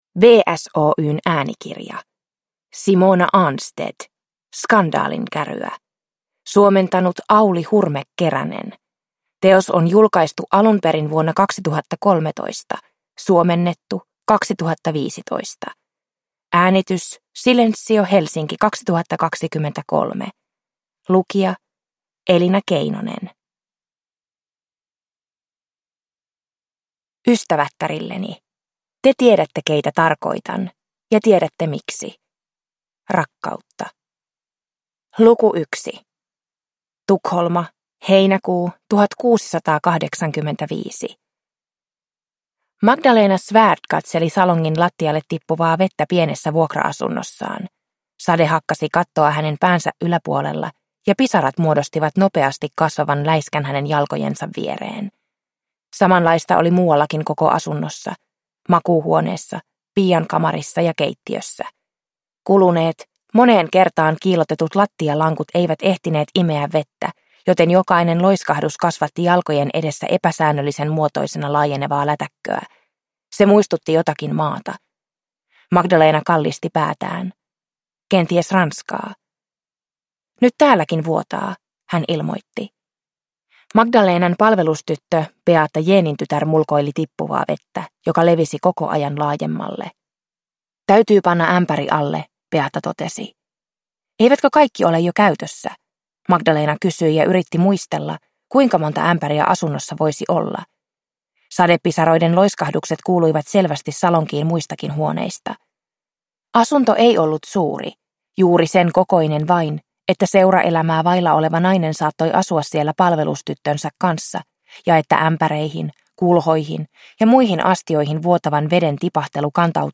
Skandaalinkäryä – Ljudbok – Laddas ner